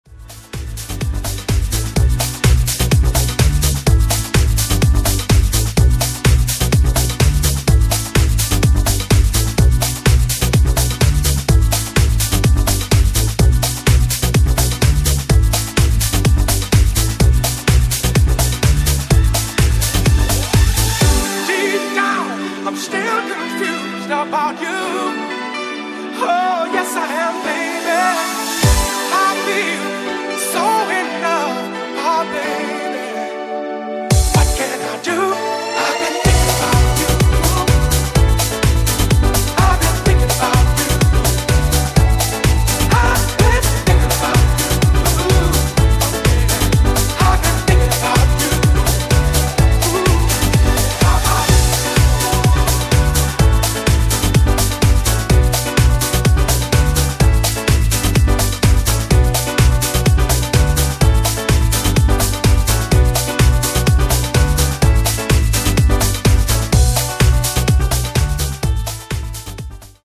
NU-Disco Remix